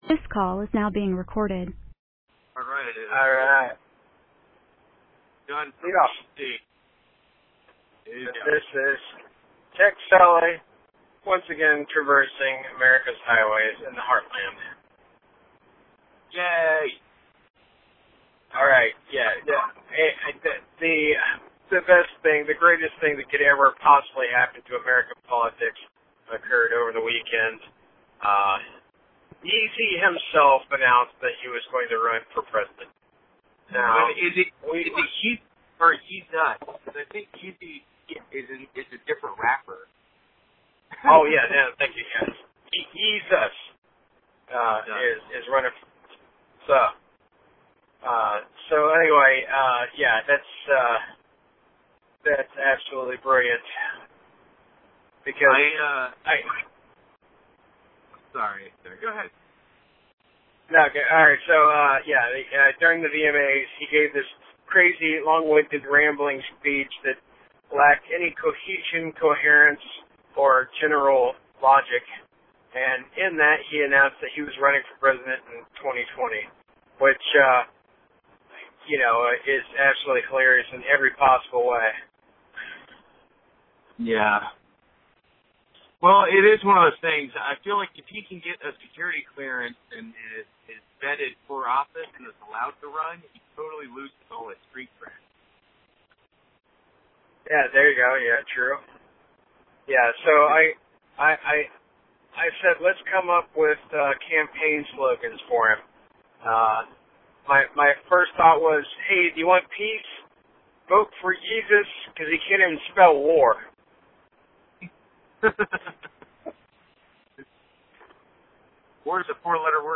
This week the brothers crack each other up with a handful of Kanye West campaign slogans. Then they both get distracted and complain about abused formulas in movies.